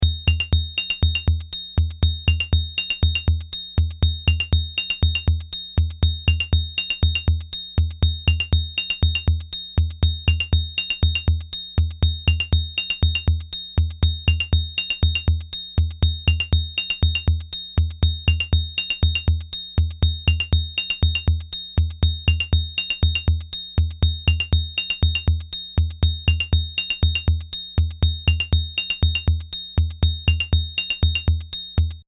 Bucle de percusión electrónica
Música electrónica
melodía
repetitivo
sintetizador